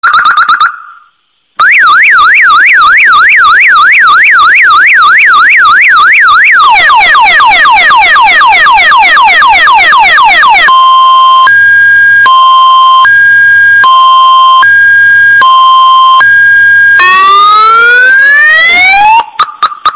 Toque Alarme de Carro
Toque Car Lock Som de Motor para Toque de Celular
Categoria: Sons de sinos e apitos
Descrição: Com o som inconfundível do alarme de carro, você vai garantir que nunca perca uma chamada ou mensagem importante.
toque-alarme-de-carro-pt-www_tiengdong_com.mp3